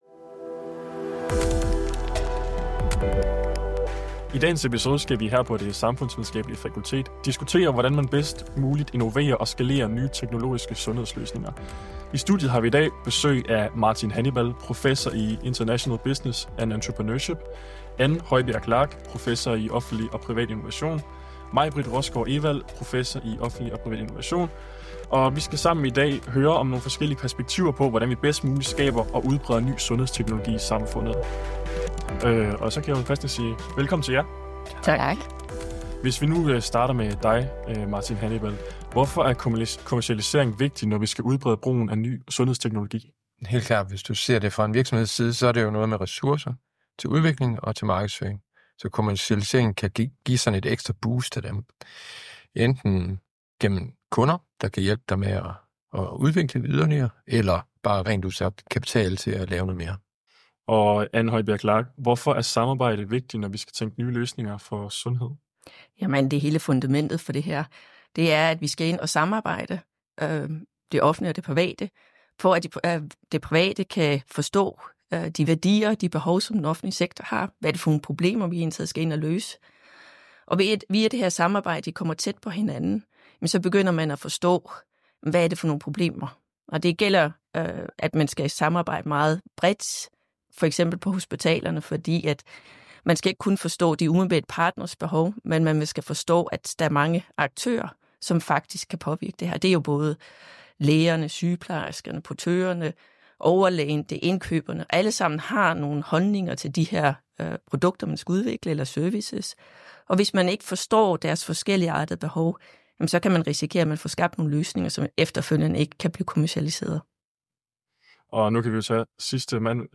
Gæsterne er eksperter inden for offentlig og privat innovation og forbrugerkultur samt entreprenørskab.